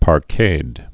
(pär-kād)